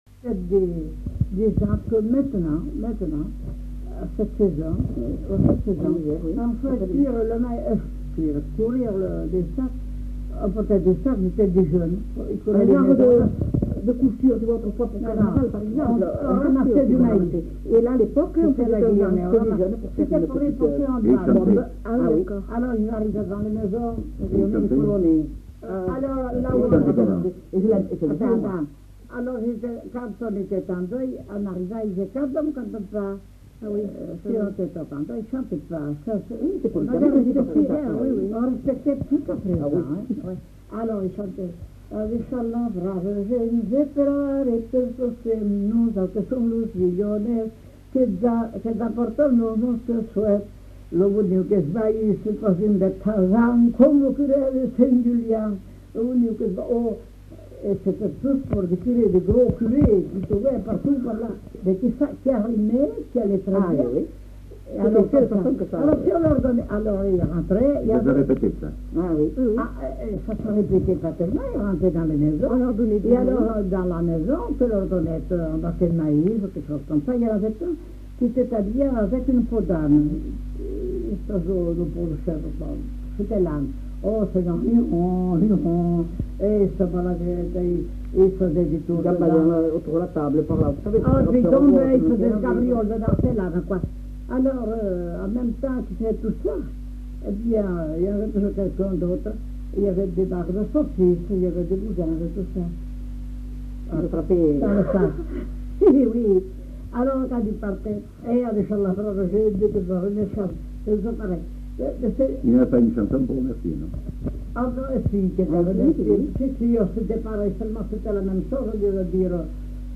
Aire culturelle : Marsan
Genre : chant
Effectif : 1
Type de voix : voix de femme
Production du son : chanté
Contient avant et après le chant des commentaires sur la coutume de "l'aguilhonè".